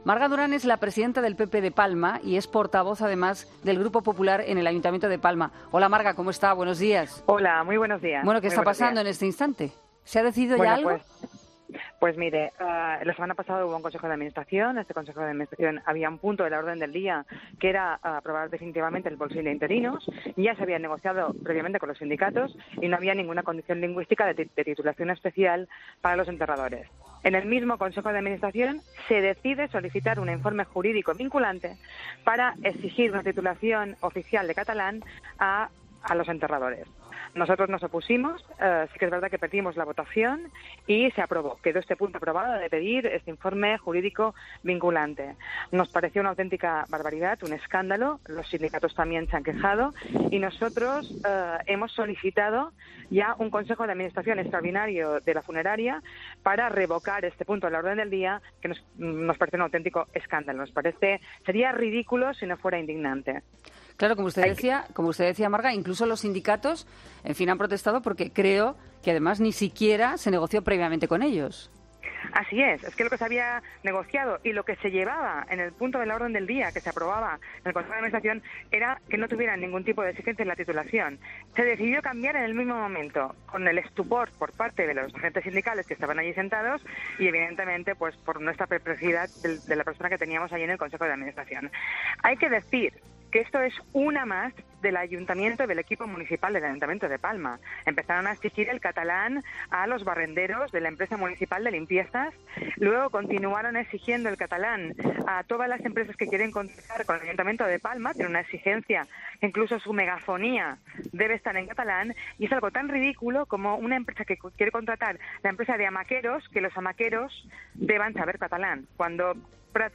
Entrevista a Marga Durán, Presidenta del PP de Palma y Portavoz del Grupo Popular en el Ayuntamiento